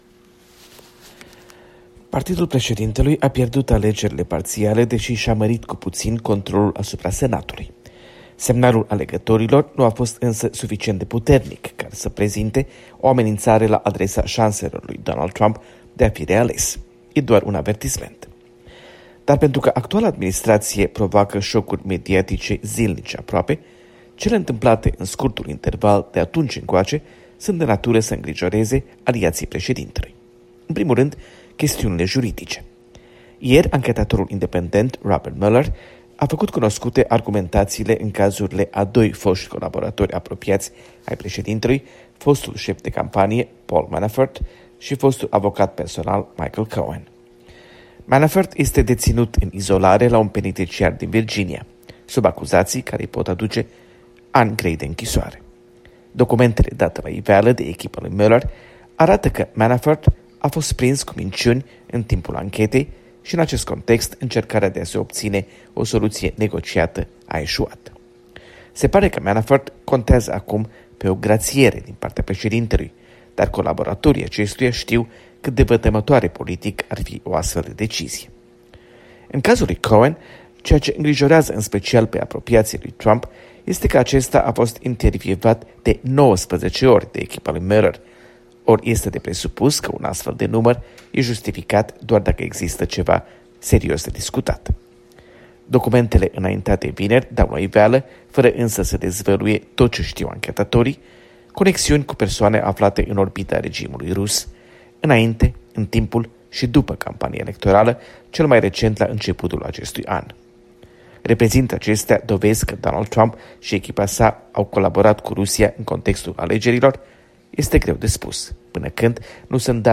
Corespondența zilei de la Washington.